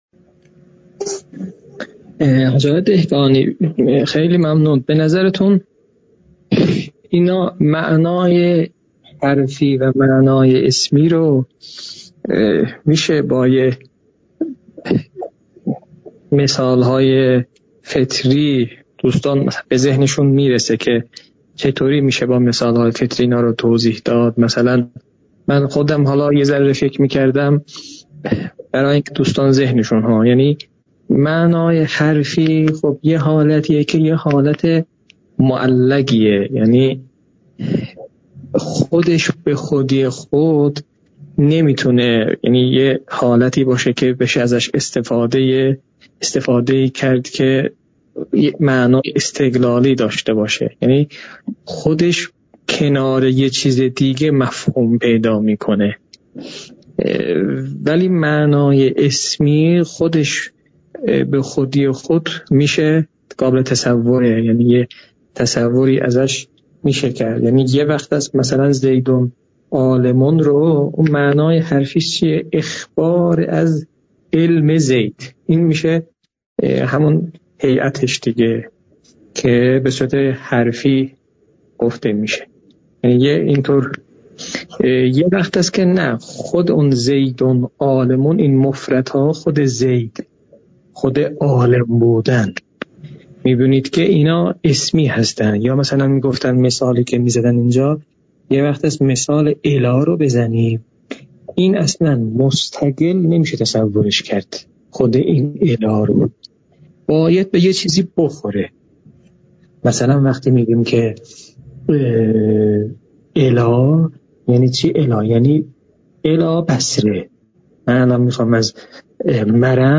تدریس كتاب حلقه ثانیه